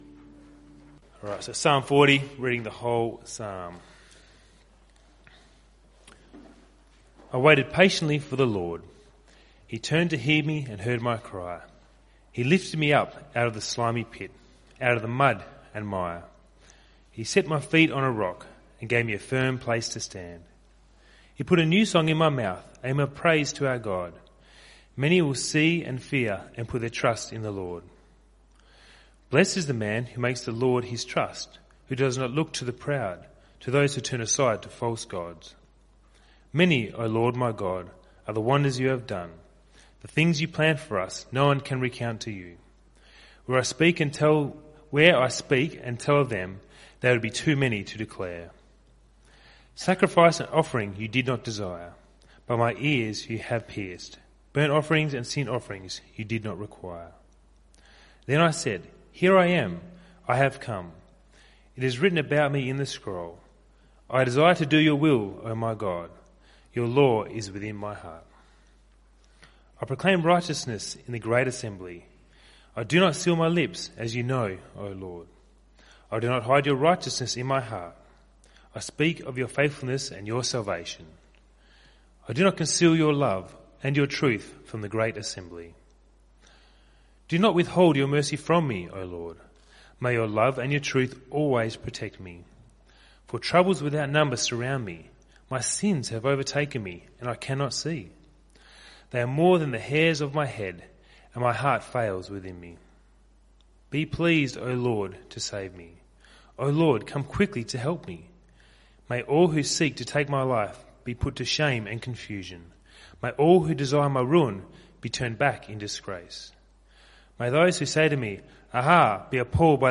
CBC Service